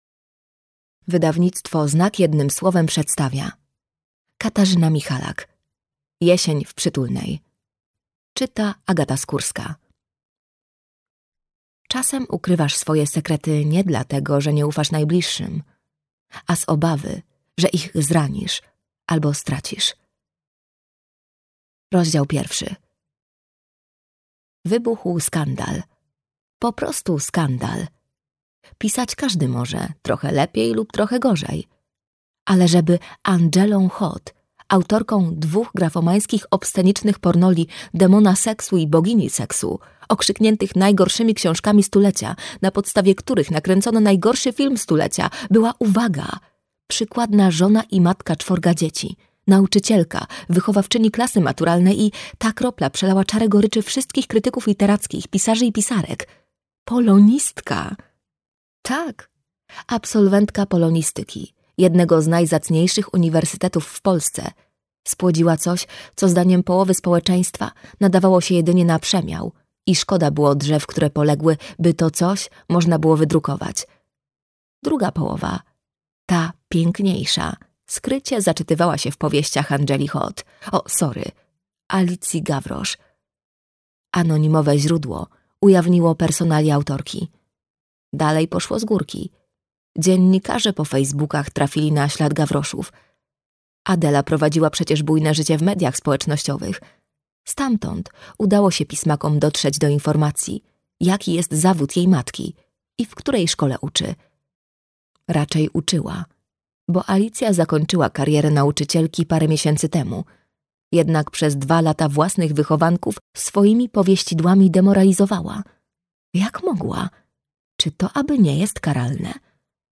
Jesień w Przytulnej - Katarzyna Michalak - audiobook